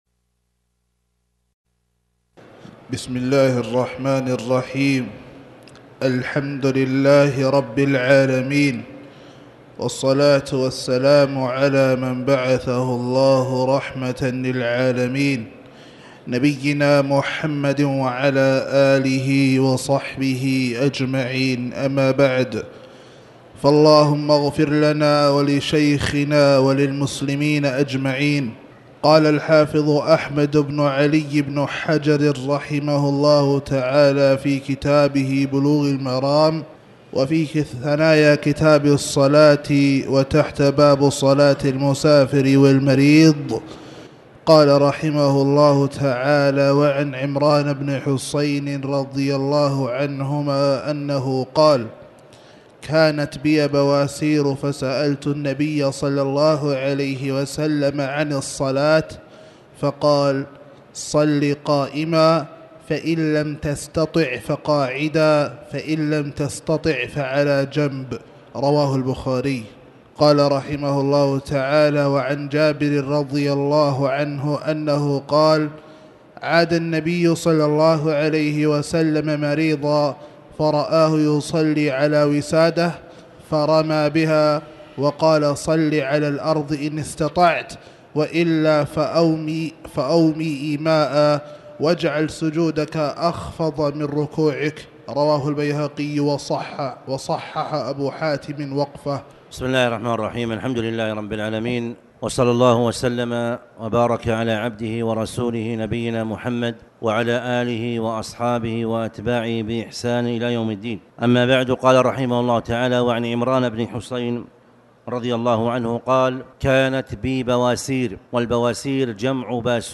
تاريخ النشر ٢٨ صفر ١٤٣٩ هـ المكان: المسجد الحرام الشيخ